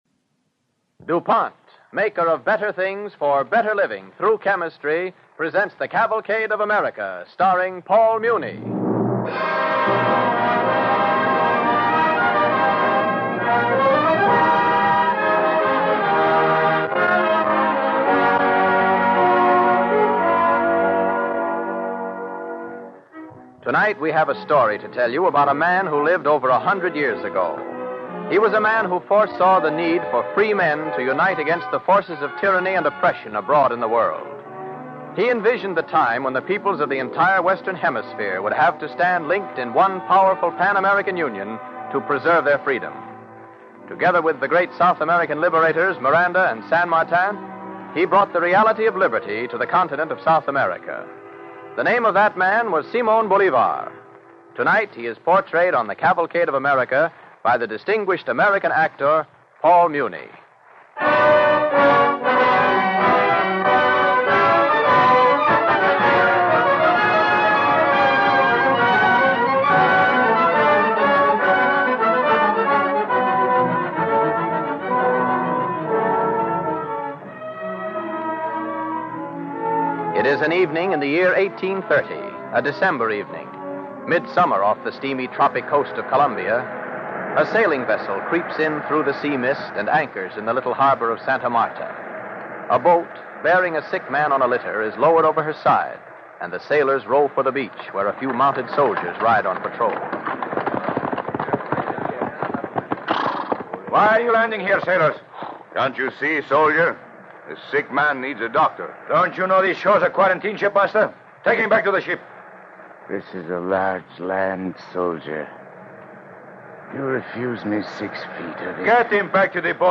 Cavalcade of America Radio Program
Bolivar the Liberator, starring Paul Muni